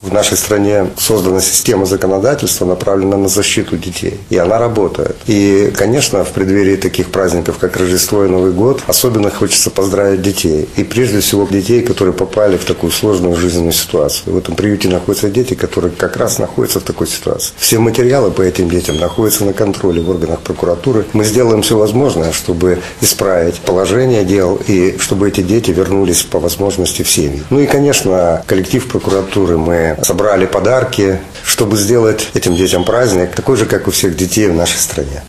Особое внимание тем, кто оказался сегодня в непростых условиях, — отметил прокурор Брестской области Виктор Климов.